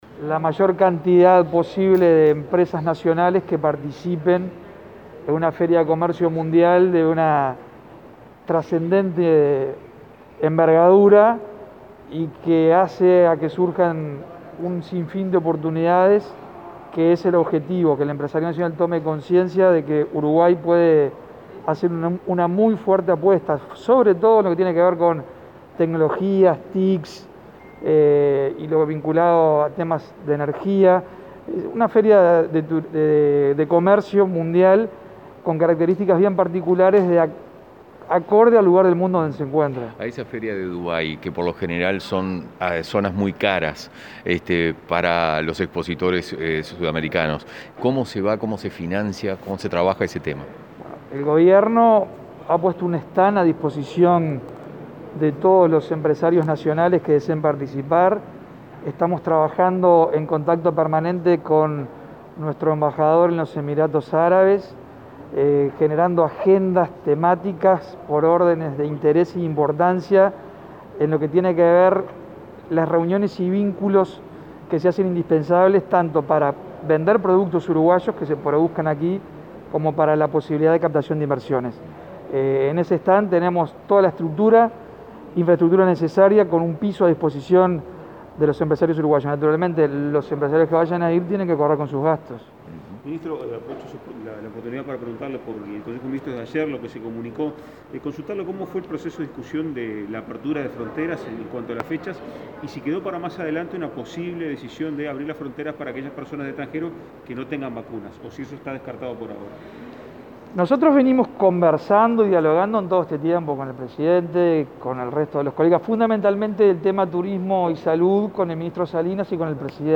Palabras del ministro Germán Cardoso y el subsecretario Remo Monzeglio, tras el acuerdo ministerial
El ministro de Turismo, Germán Cardoso, y el subsecretario Remo Monzeglio, informaron a la prensa sobre la reunión con el presidente Luis Lacalle Pou